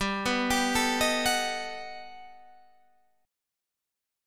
Listen to G+M9 strummed